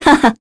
Scarlet-vox-Happy4_kr.wav